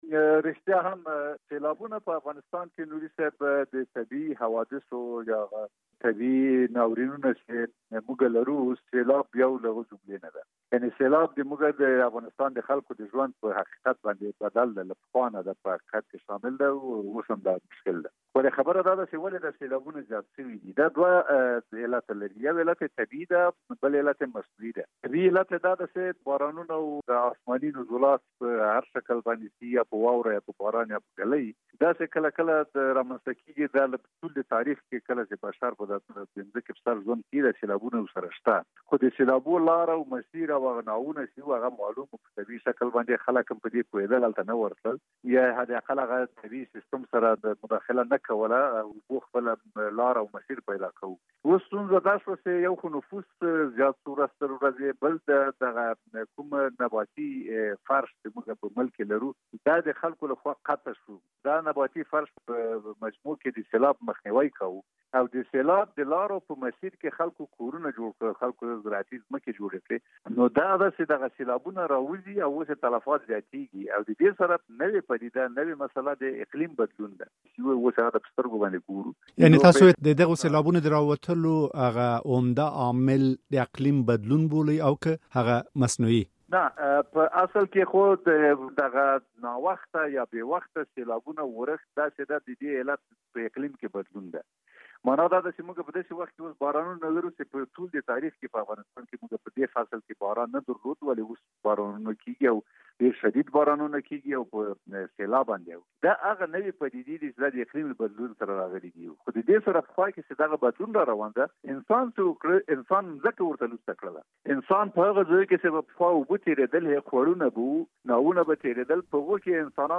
مدقق سره مرکه